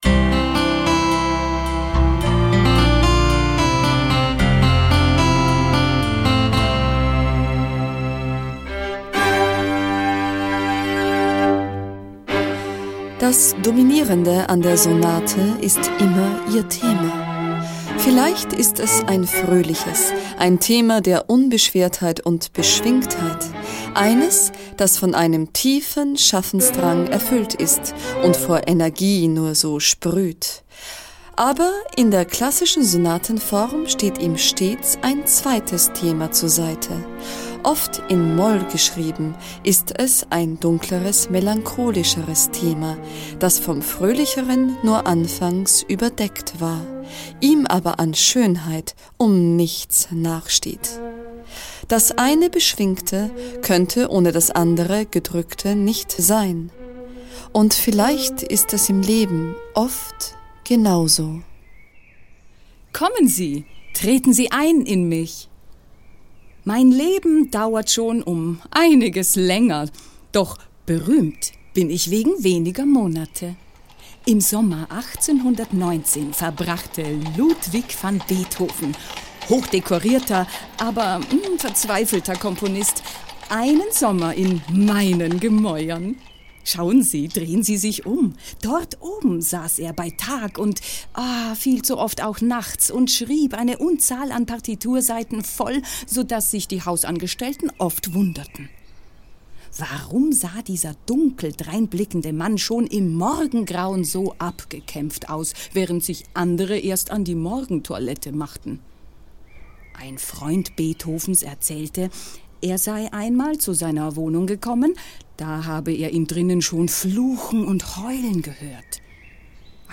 Musik: Große Fuge, OP 133, Alban Berg Quartett, 1997 EMI Records
Beethoven: Missa Solemnis, 1996 Deutsche Grammophon GmbH, Hamburg